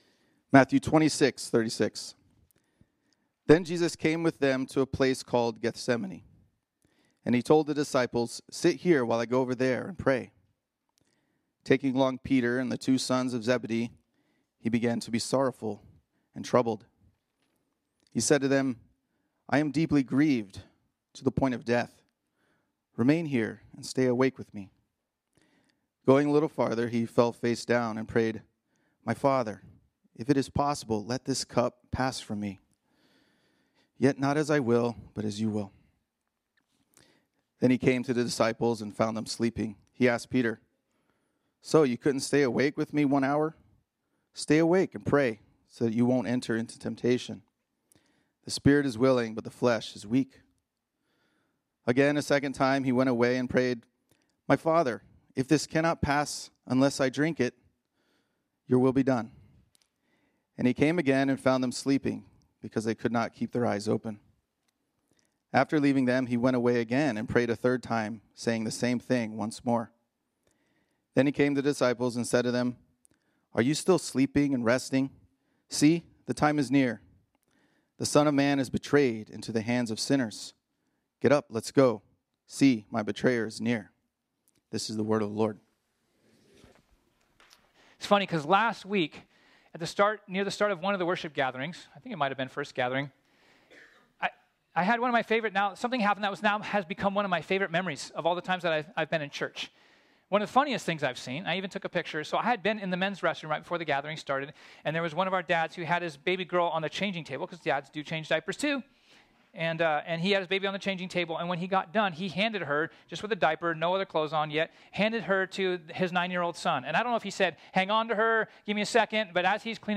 ” our sermon series on the Gospel of Matthew.